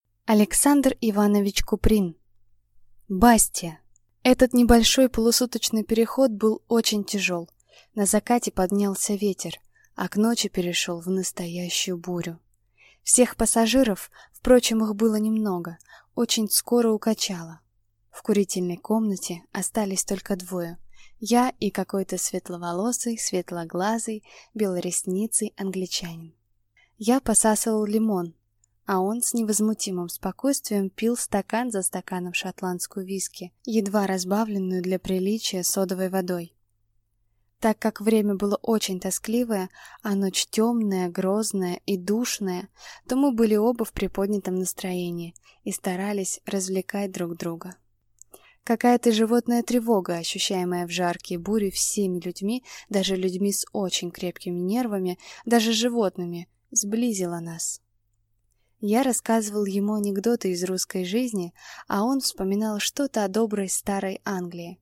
Аудиокнига Бастиа